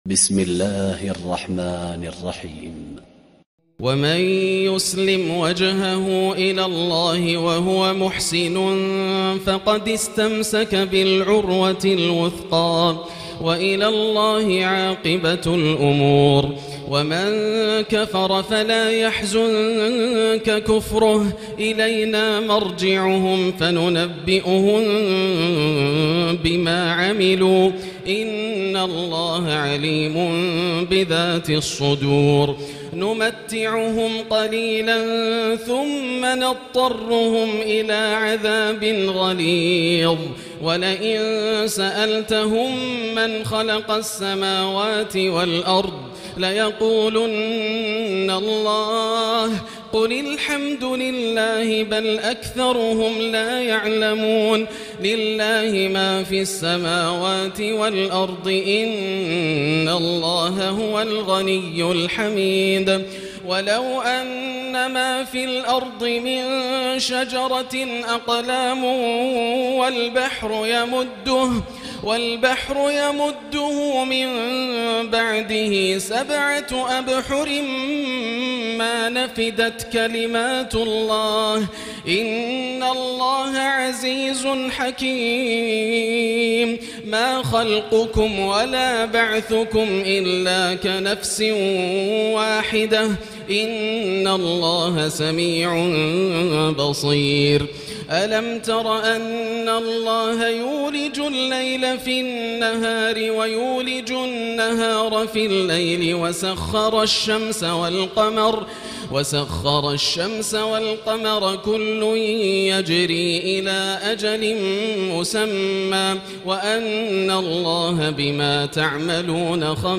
الليلة العشرون - ما تيسر من سورة "لقمان"من آية 22 وسورة السجدة وما تيسر من سورة "الأحزاب" حتى آية34 > الليالي الكاملة > رمضان 1439هـ > التراويح - تلاوات ياسر الدوسري